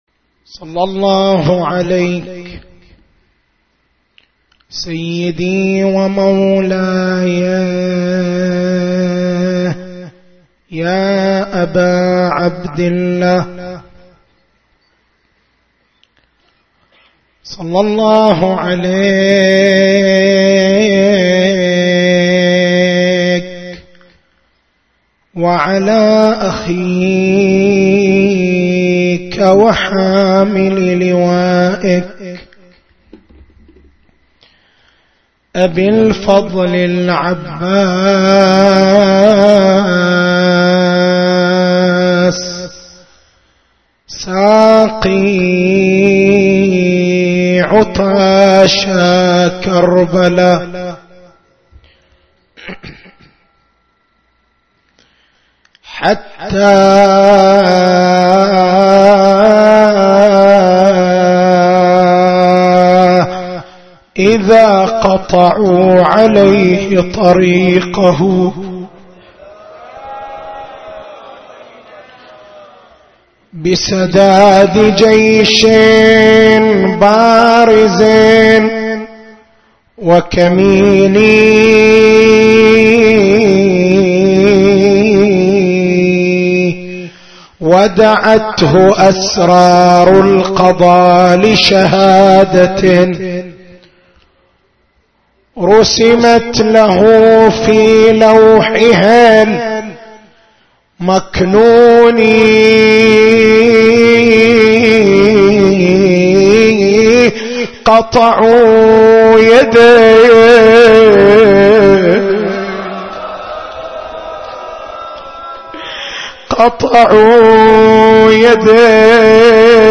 تاريخ المحاضرة: 07/01/1434 نقاط البحث: بيان المقصود من نظرية المؤامرة هل نظرية المؤامرة واقع أم مجرّد خيال؟